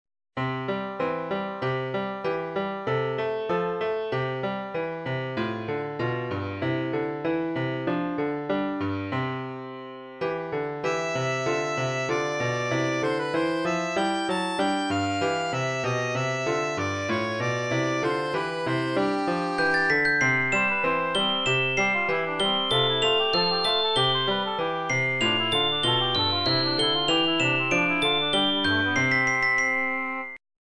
Esempi di musica realizzata con il sistema temperato